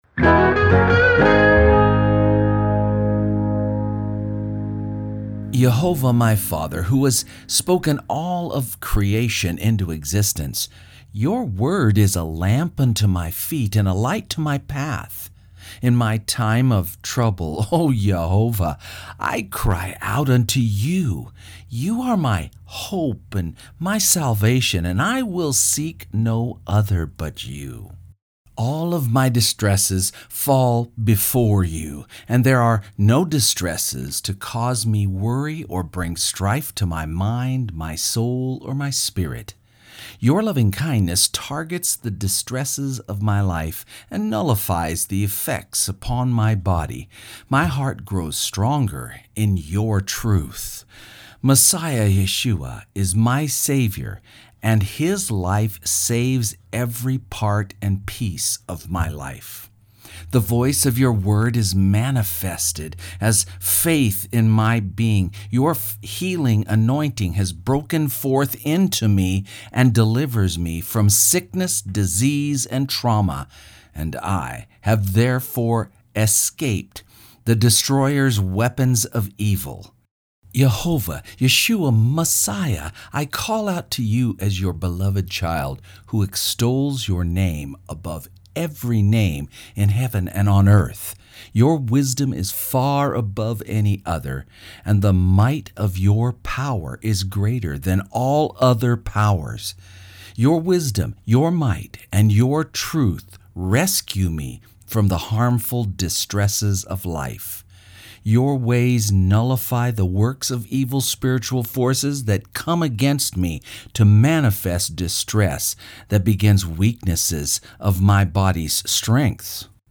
Reading-LovingkindnessofHisWord.mp3